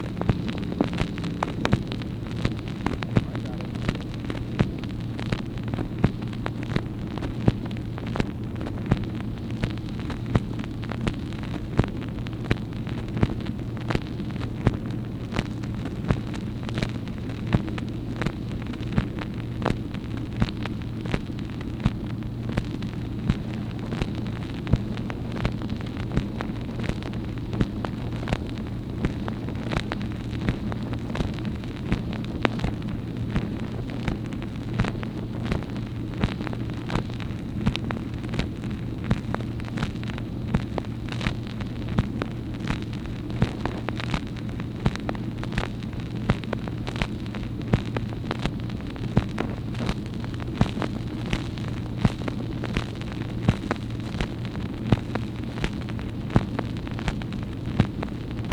MACHINE NOISE, April 30, 1964
Secret White House Tapes | Lyndon B. Johnson Presidency